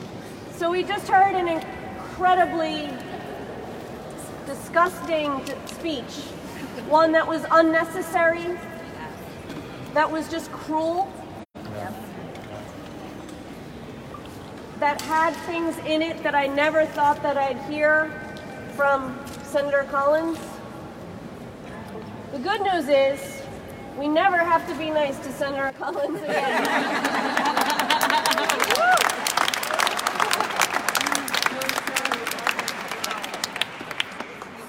You can hear a portion of one of them here:
collins-speech-aftermath.mp3